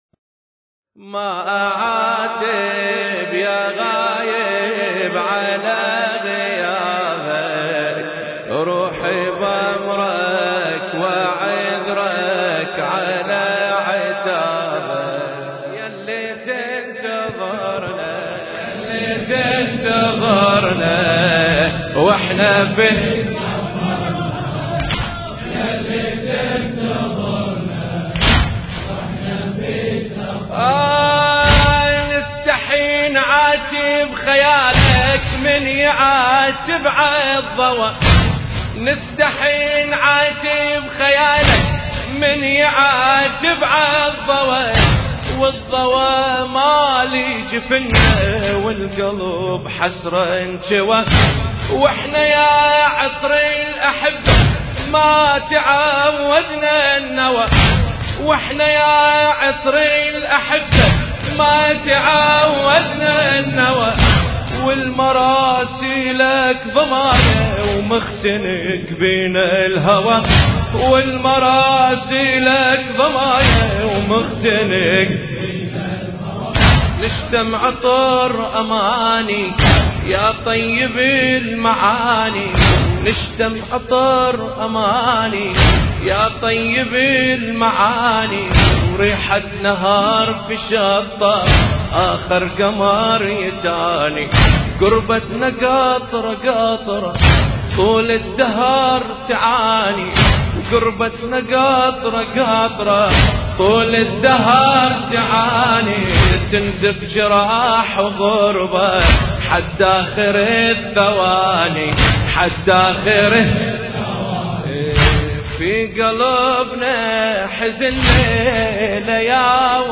المكان: مأتم السنابس الكبيرة - البحرين